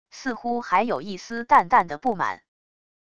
似乎还有一丝淡淡的不满wav音频生成系统WAV Audio Player